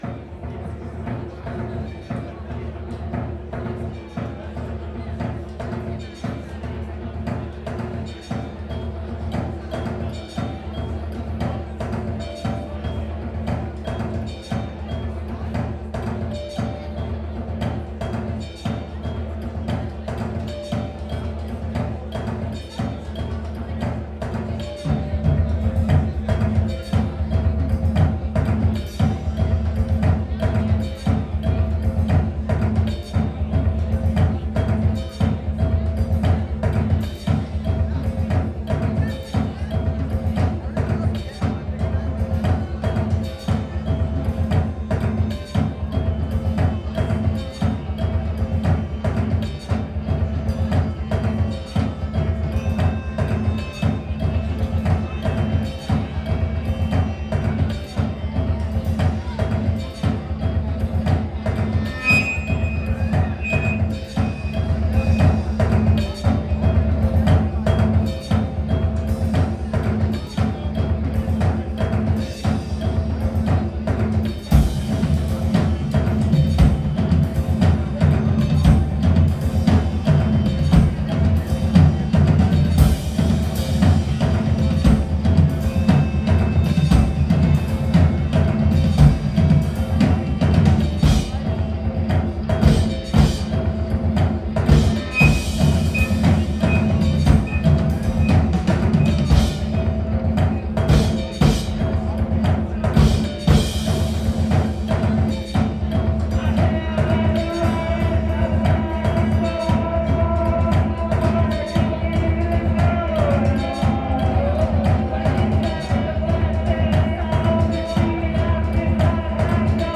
Neumo’s – Seattle, WA